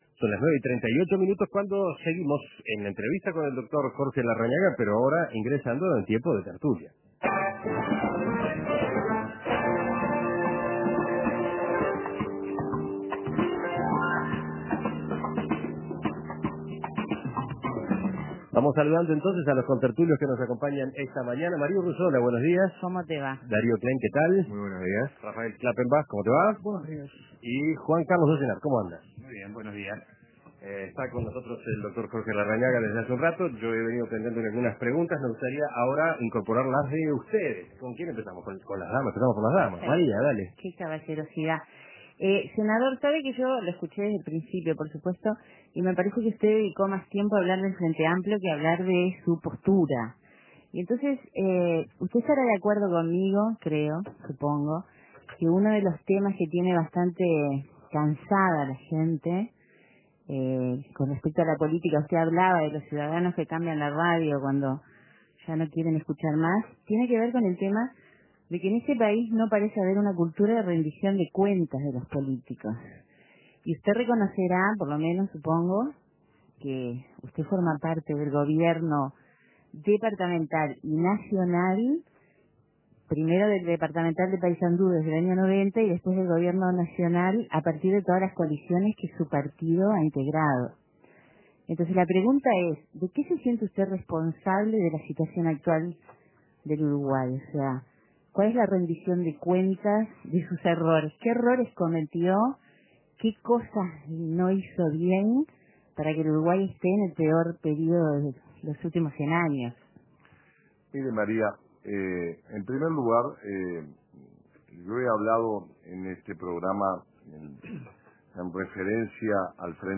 Los contertulios dialogan con el candidato a la Presidencia por el Partido Nacional, Jorge Larrañaga